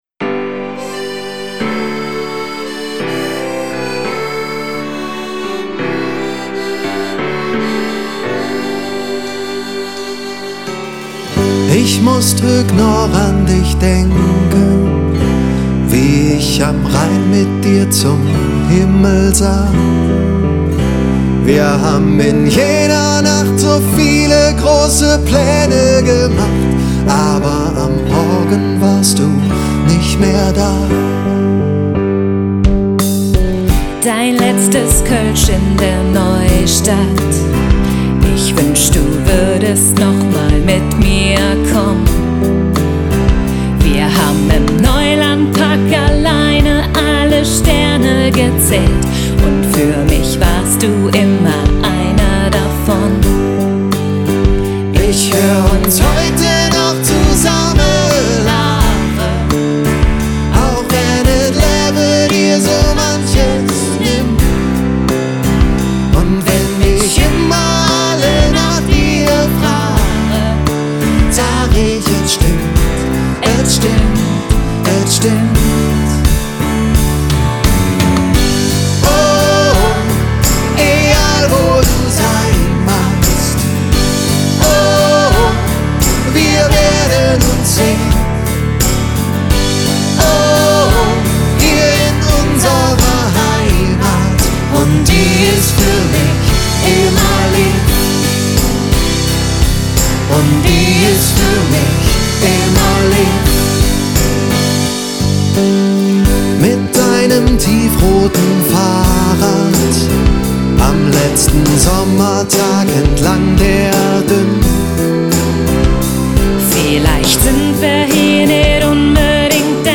Diesmal haben wir etwas zum Schunkeln für euch: